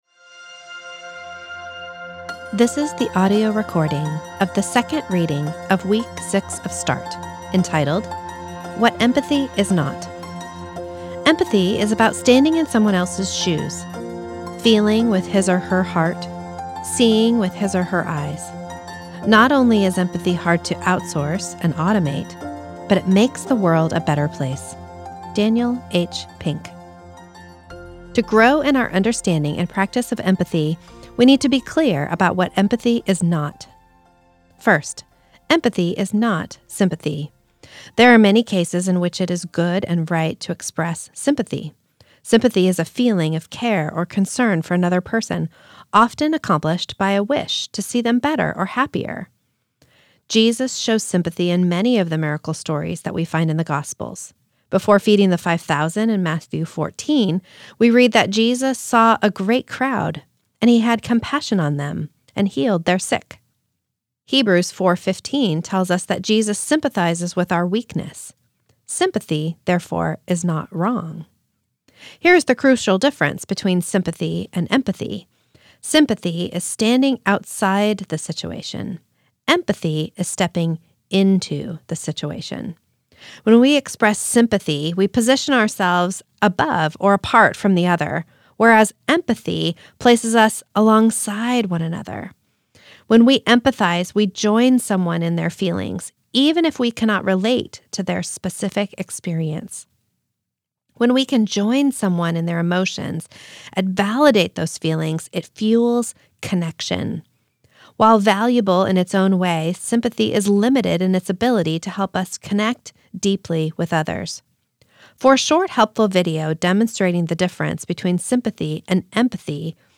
This is the audio recording of the second reading of week six of Start, entitled Counter-Formational Practices and Relationships.